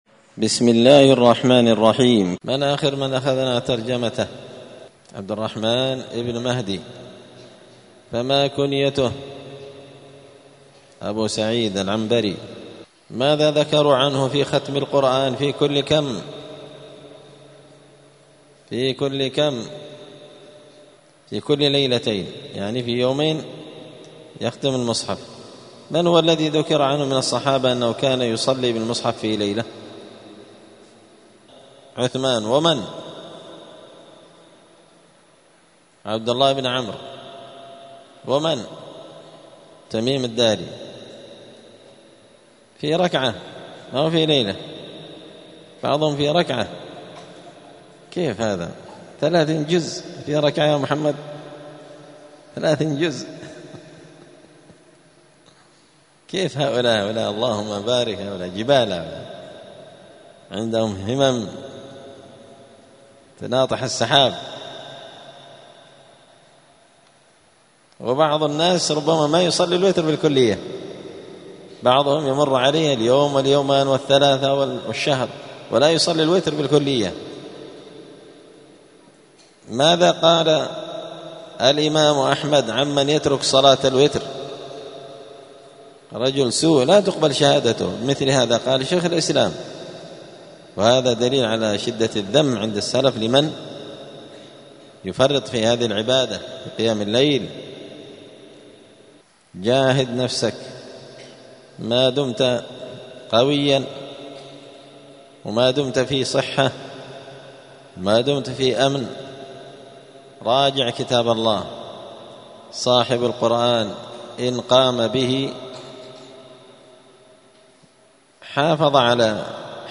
*الدرس الرابع والثمانون (84) باب التعريف با لنقاد يحيى بن معين*